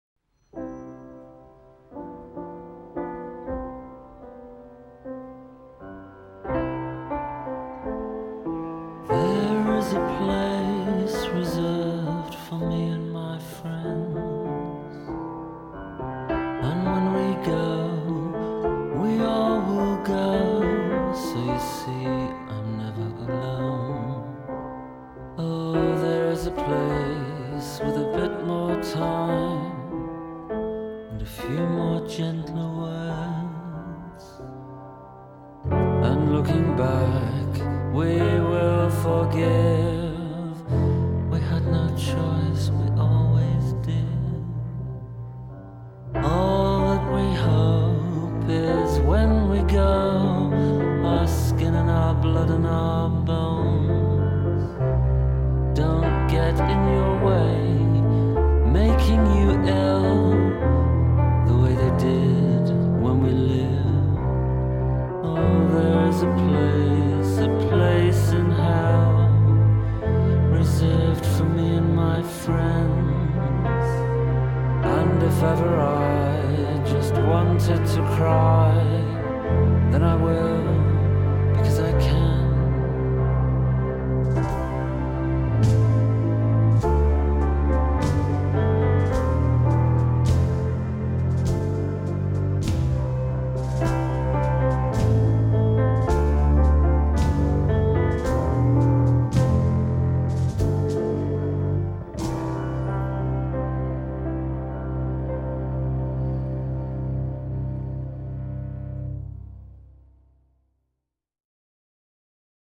haunting piano ballad